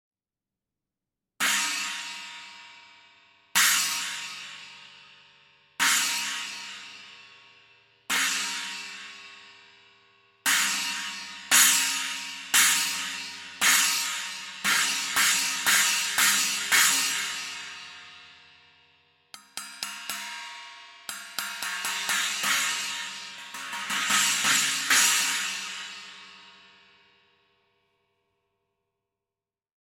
10″ Armor Trash Splash Cymbals (Approx 290 grams):
10__Armor-Trash_Splash.mp3